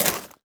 Combo FinalVinyl.wav